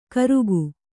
♪ karugu